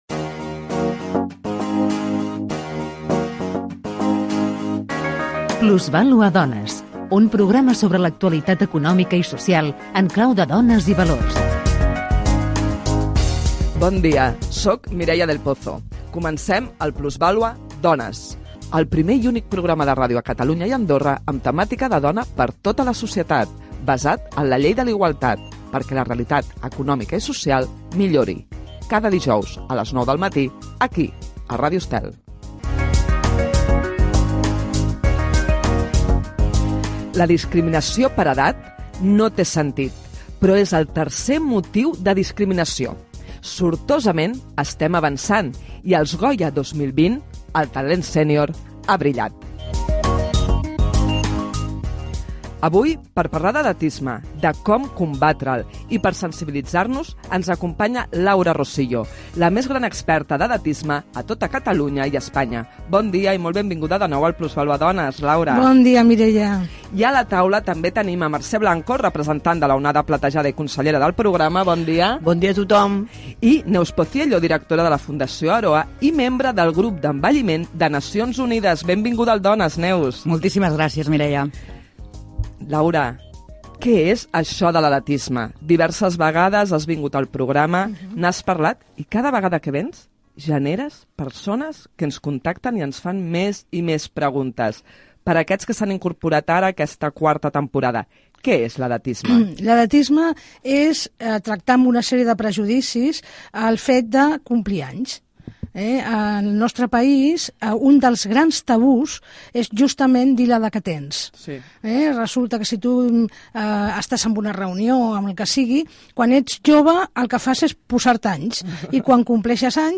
Divulgació